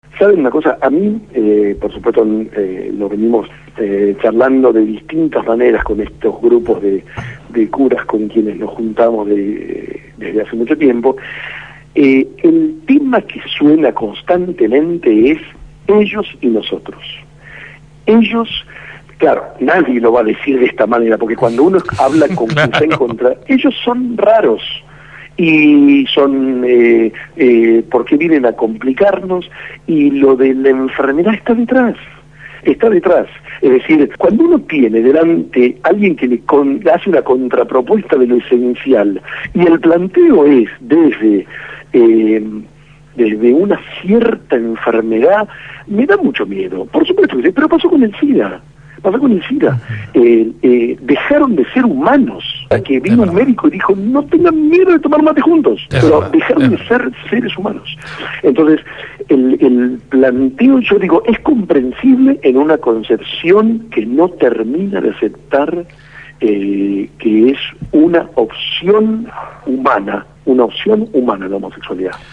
Sacerdote por la opción por los pobres se pronunció en la Gráfica